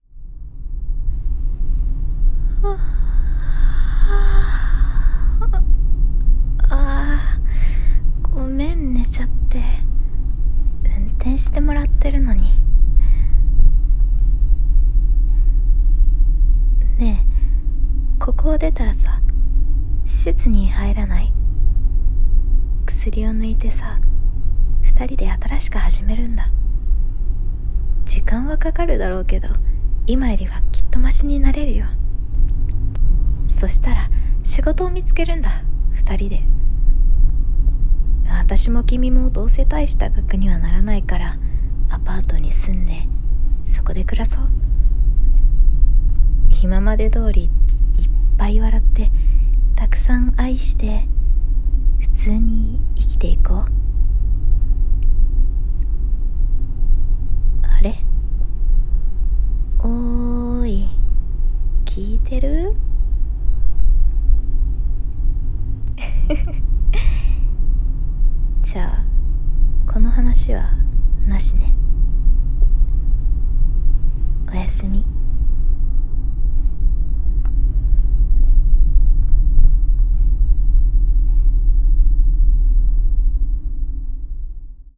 仮底 【一人声劇台本】